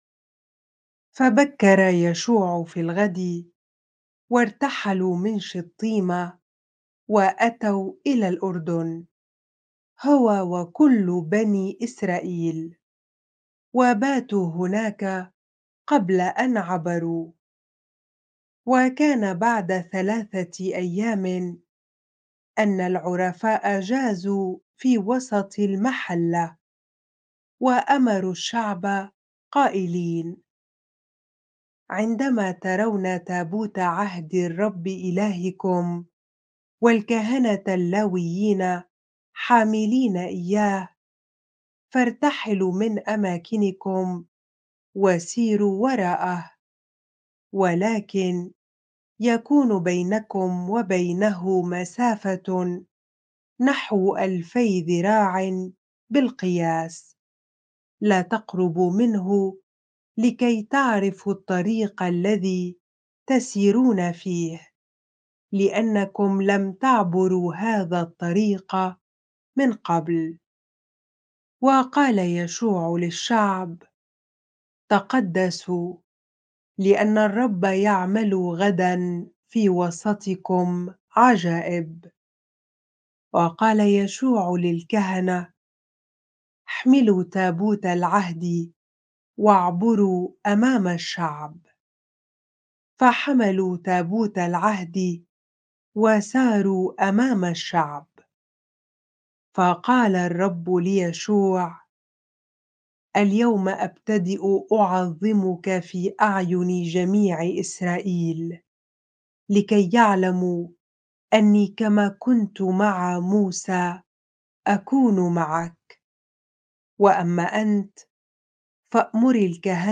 bible-reading-joshua 3 ar